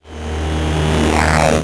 VEC3 Reverse FX
VEC3 FX Reverse 53.wav